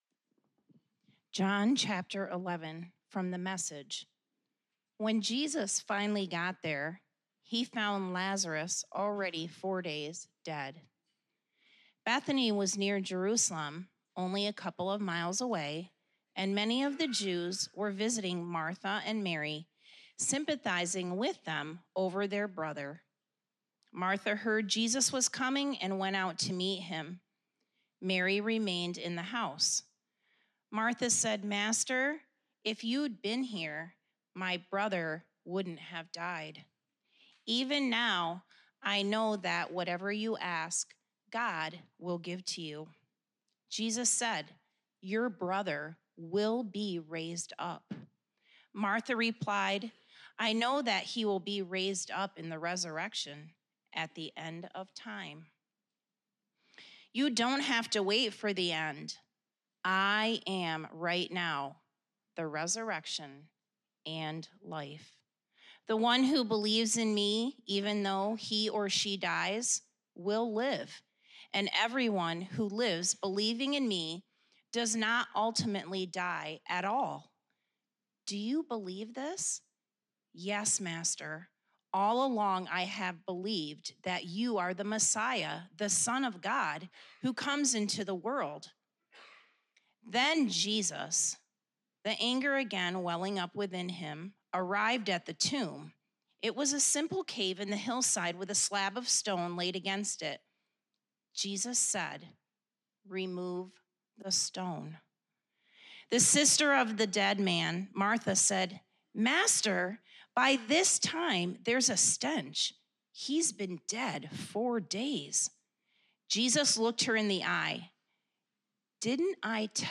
April 21, 2019 AM | I AM the Resurrection and the Life - Georgetown Christian Reformed Church